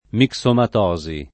mixomatosi [ mik S omat 0@ i ]